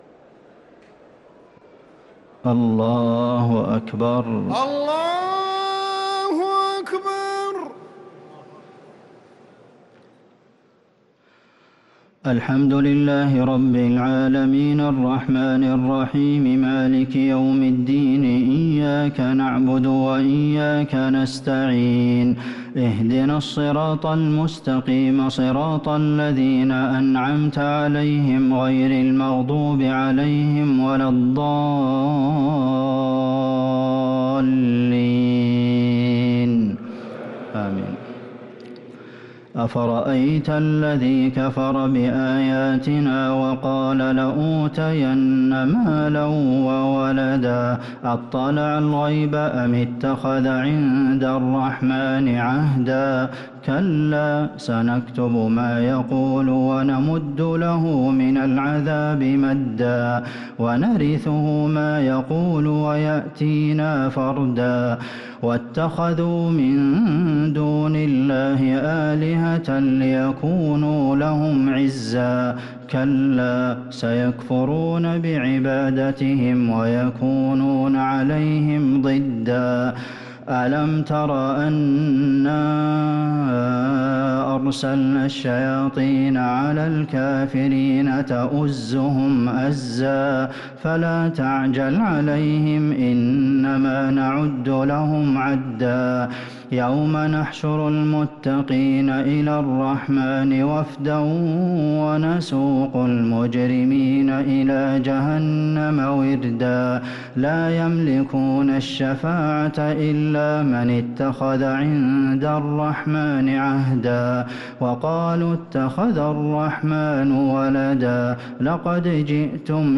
صلاة التراويح ليلة 21 رمضان 1444 للقارئ عبدالمحسن القاسم - التسليمتان الأخيرتان صلاة التراويح